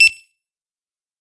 barcodescannerbeep.mp3